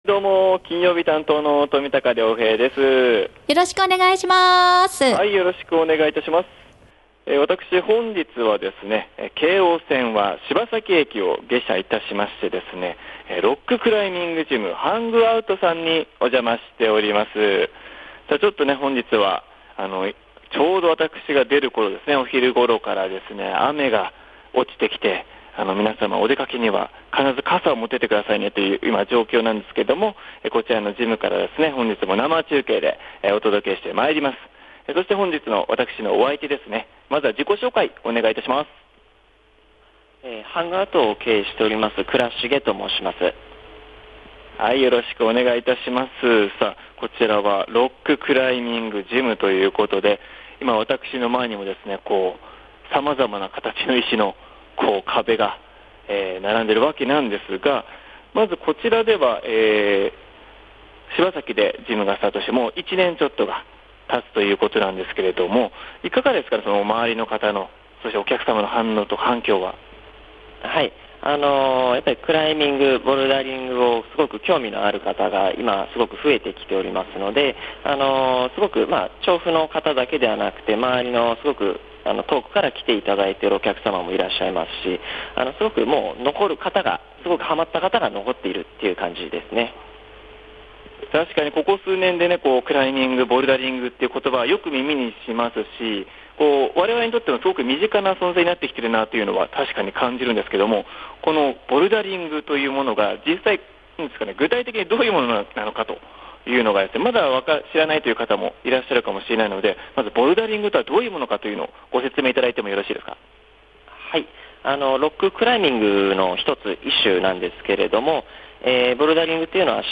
午後のカフェテラス 街角レポート
本日はあいにくの雨模様でしたね～小雨がぱらつく中でかけてきましたよ（＾＾）／